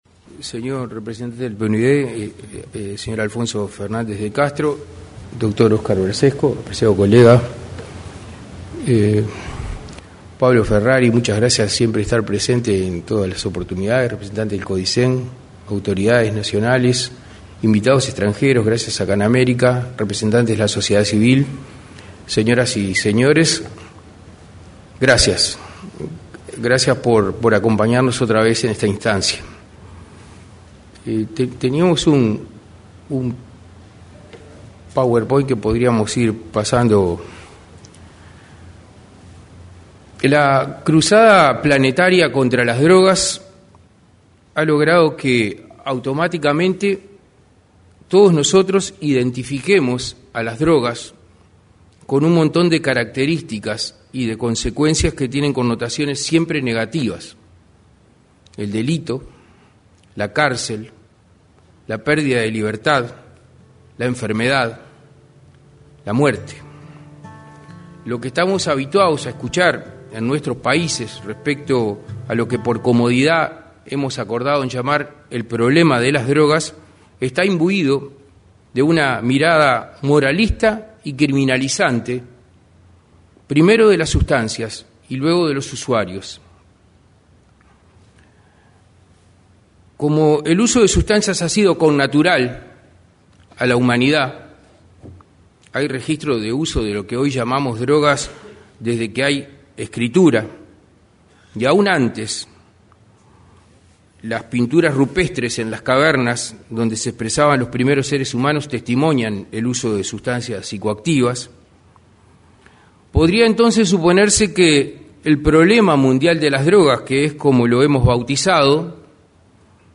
Discurso del secretario general de la Secretaría Nacional de Drogas, Daniel Radío